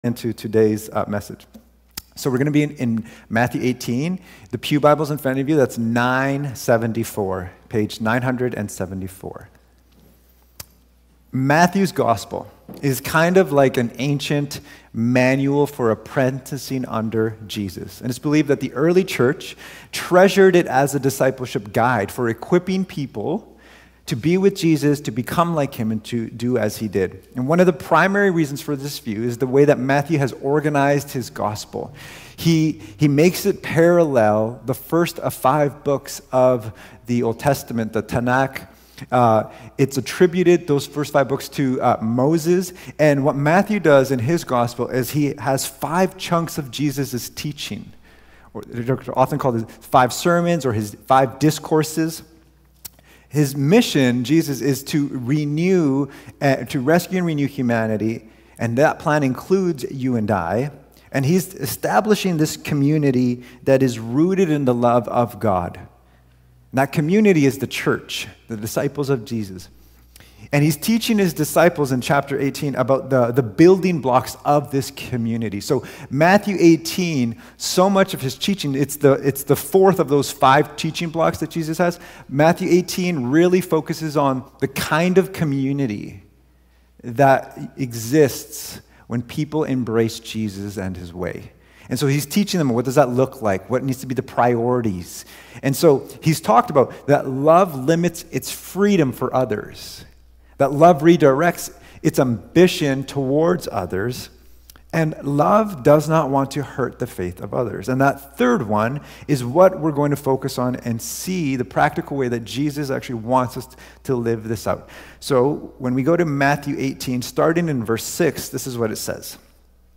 Cascades Church Sermons Love Does No Harm | Matthew 18:6-9 Play Episode Pause Episode Mute/Unmute Episode Rewind 10 Seconds 1x Fast Forward 30 seconds 00:00 / 38:35 Subscribe Share Apple Podcasts RSS Feed Share Link Embed